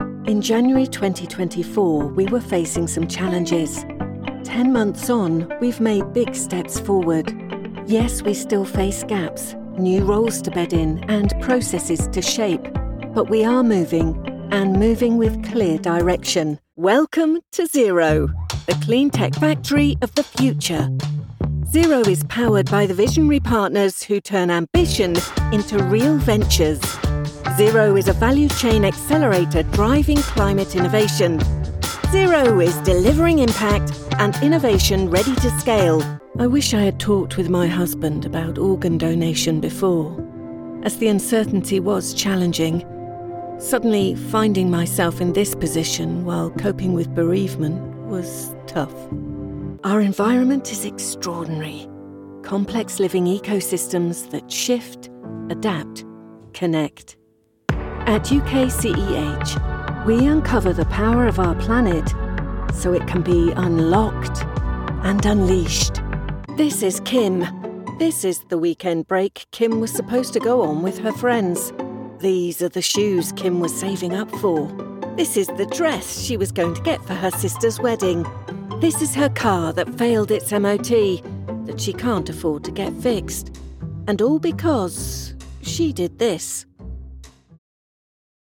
Narración
Mi voz suele describirse como confiable, juguetona, inteligente y amigable.
Espacio de grabación: cabina de sesiones de la serie DW
micrófono: AKG C414 XL11
Mezzosoprano